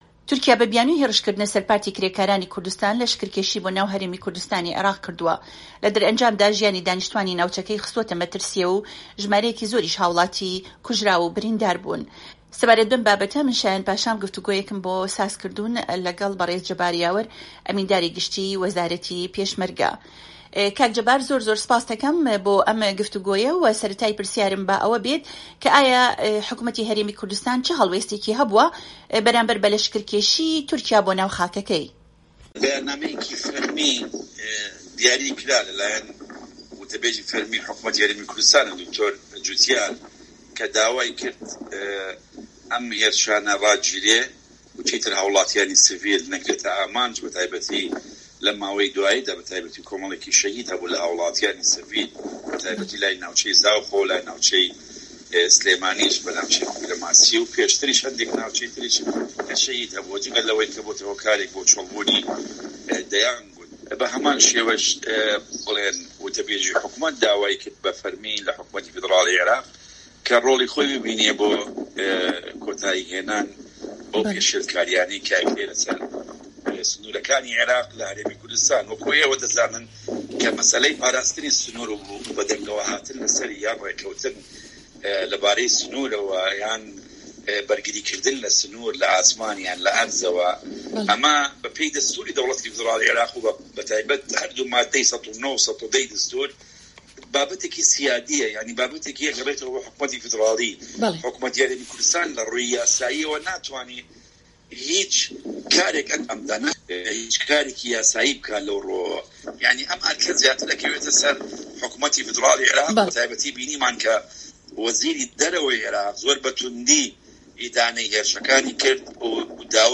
لە گفتوگۆیەکدا لەگەڵ بەرێز فەریق جەبار یاوەر ئەمینداری گشتی وەزارەتی پێشمەرگەی هەرێمی کوردستانی عیراق لەو بارەیەوە گوتی دەبێت حکومەتی عیراق هەوڵی جدیتربدات ئەم کێشەیە لەگەڵ حکومەتی تورکیا و تەنانەت پەکەکەش باسبکات و چارەسەربکات و ئەم گرفتە بەڕێگەی سیاسیەوە کۆتایی پێبهێنرێت.
گفتوگۆ لە گەڵ جەبار یاوەر